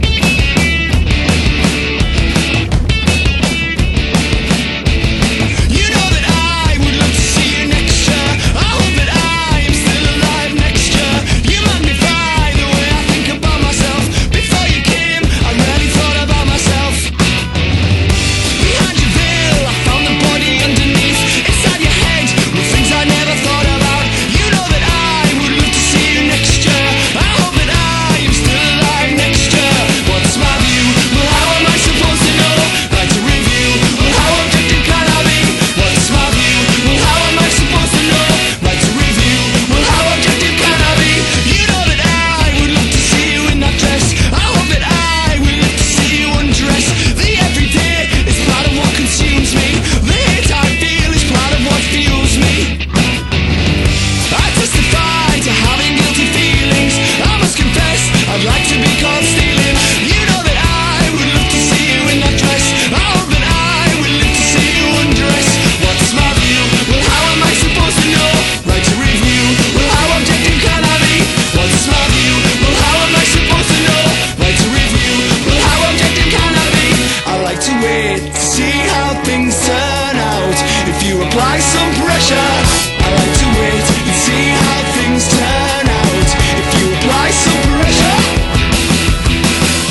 ROCK / 90''S～ / NEO-ACO/GUITAR POP / NEW WAVE / 80'S (UK)